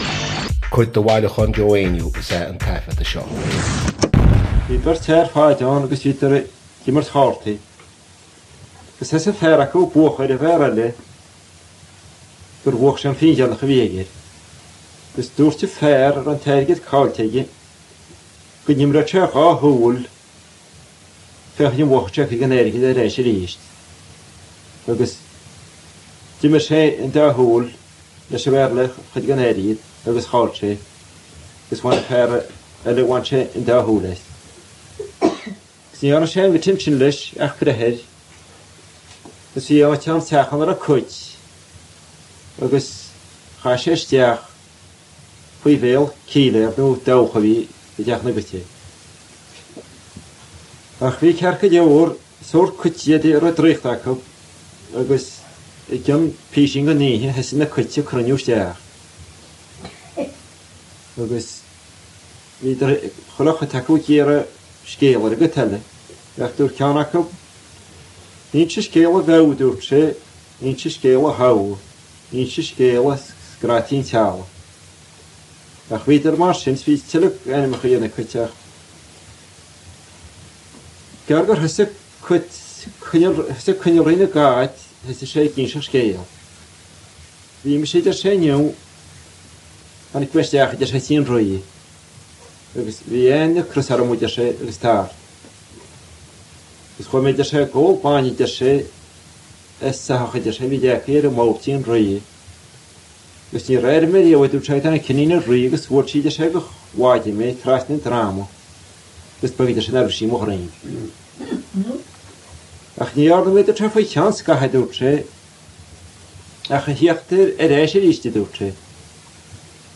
• Catagóir (Category): story.
• Suíomh an taifeadta (Recording Location): Coillín, Carna, County Galway, Ireland.